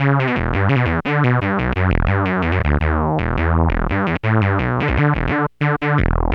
Synth 25.wav